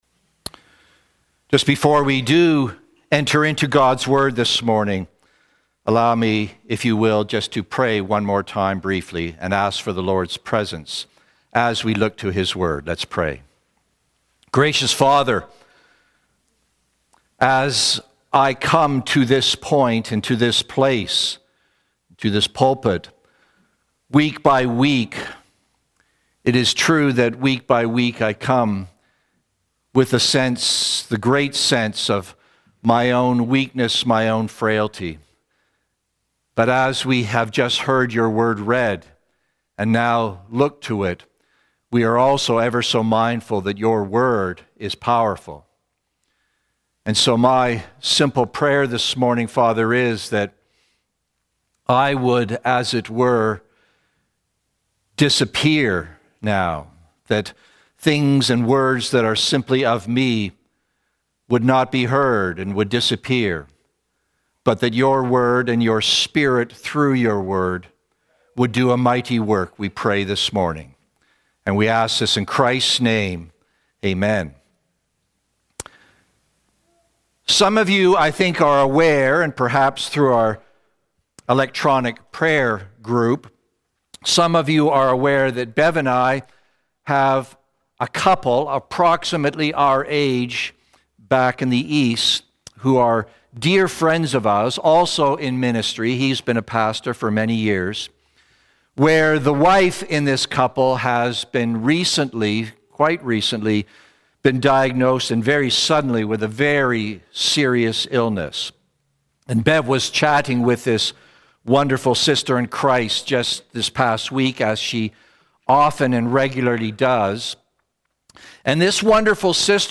Sermons | Cranbrook Fellowship Baptist